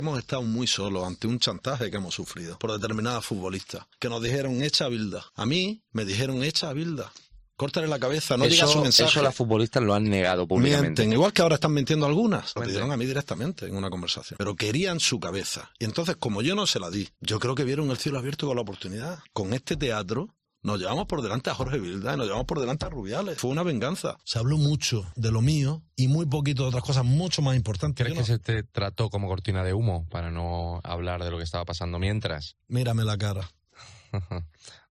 ENTREVISTA CON ALVISE